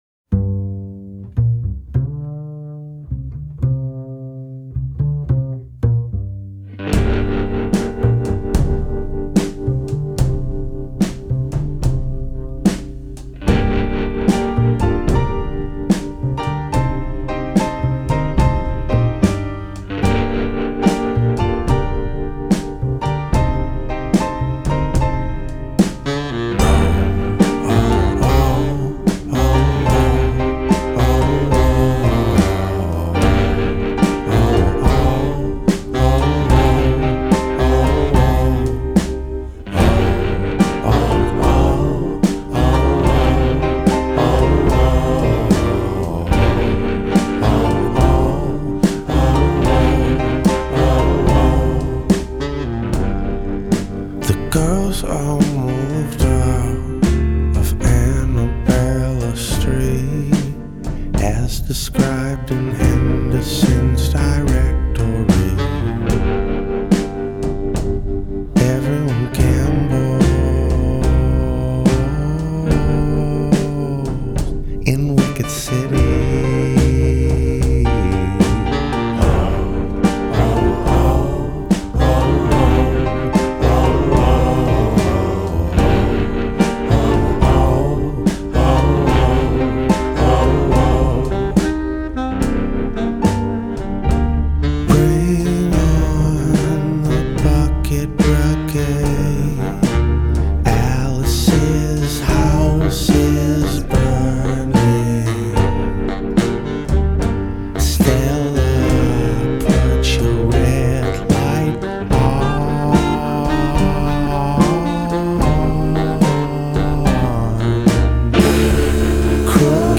vocals/guitar
piano/arrangement
saxophone
bass
drums/vocals